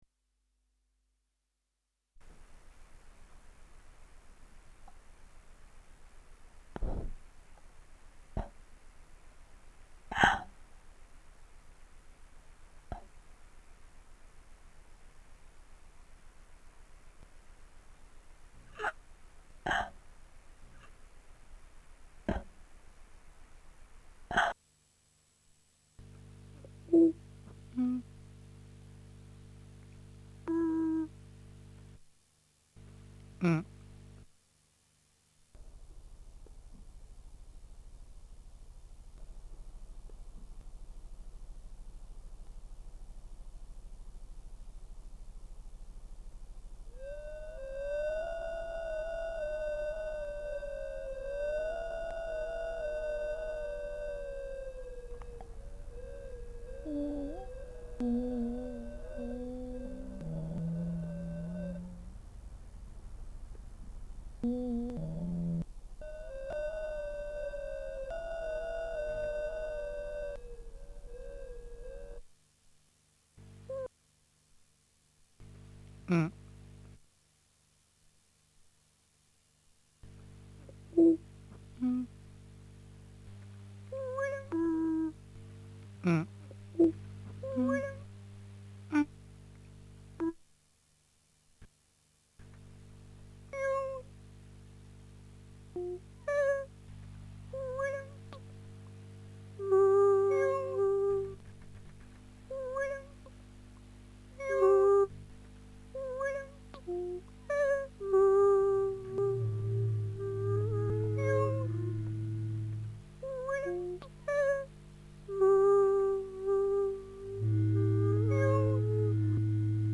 Abbildung einer kleinen Komposition mit einem geliehenen Sampler auf Minidisk am 09.01.2001.